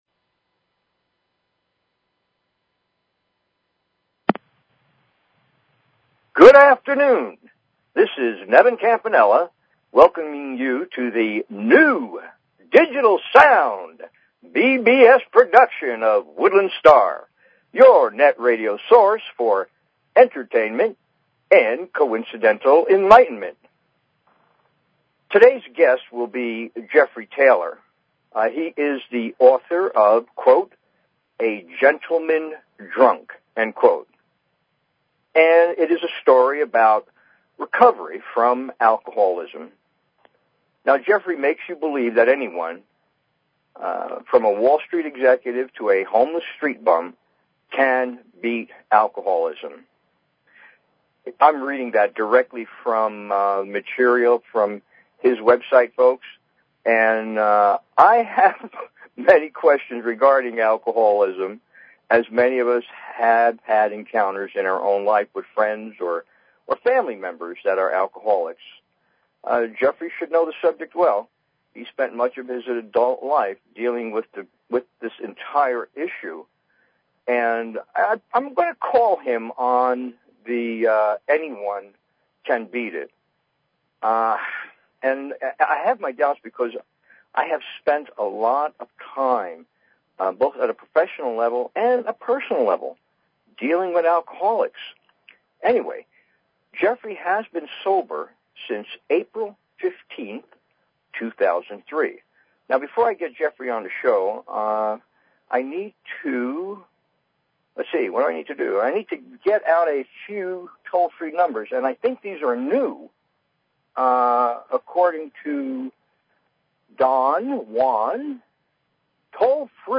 Talk Show Episode, Audio Podcast, Woodland_Stars_Radio and Courtesy of BBS Radio on , show guests , about , categorized as